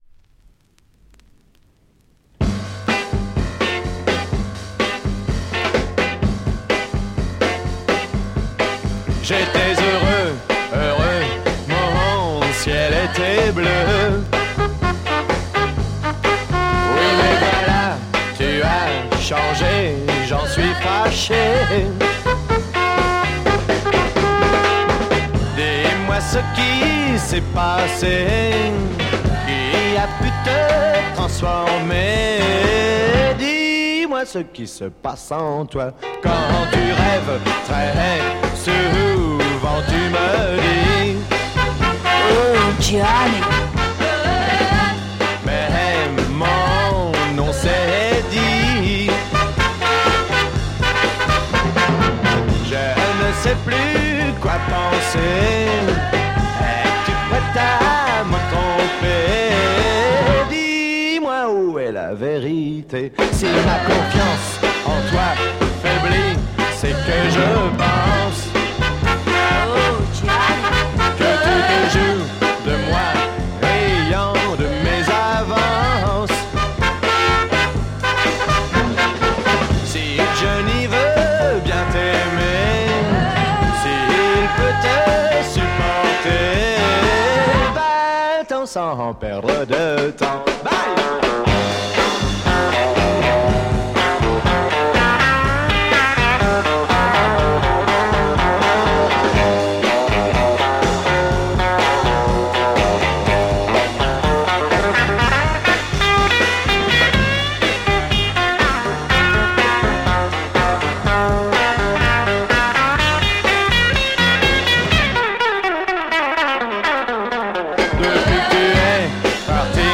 French freakbeat Mod Album
play a cool Mod freakbeat soul sound!